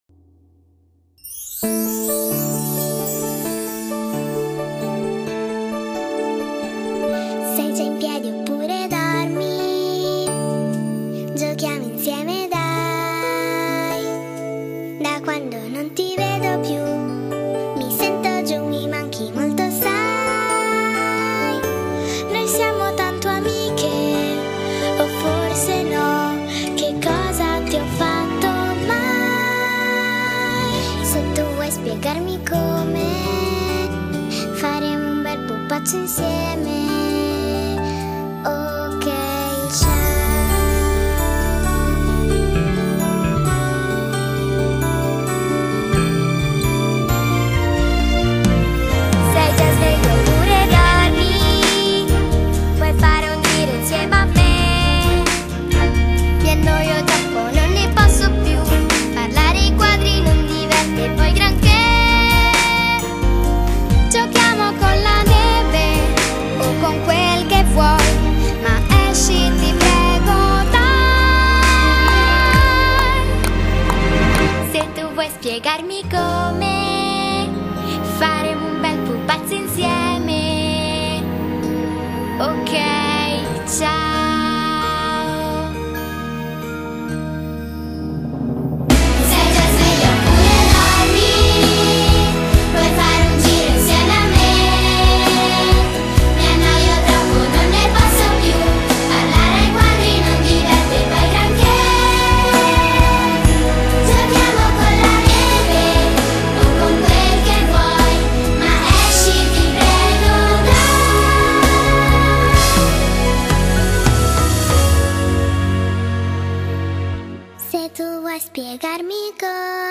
coro dei genitori- Dicembre 2018